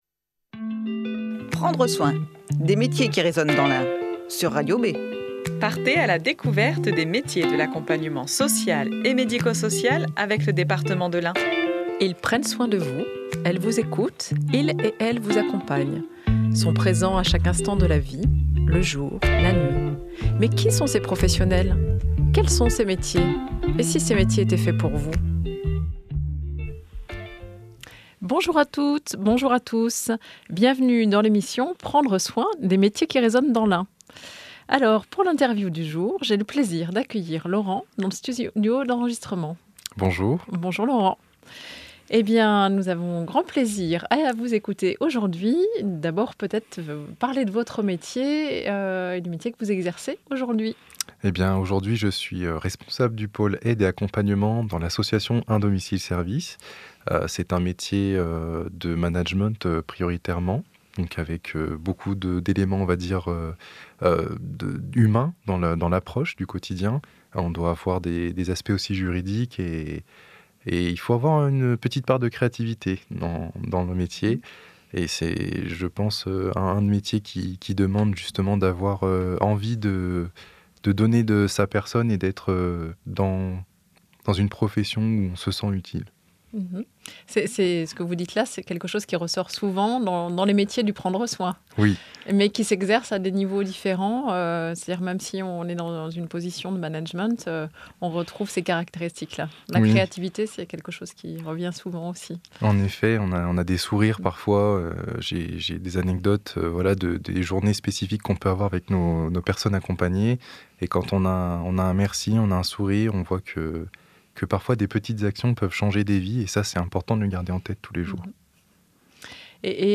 Tous les troisièmes vendredis du mois, retrouvez une "interview minute" avec un professionnel des métiers de l'accompagnement social et médico-social.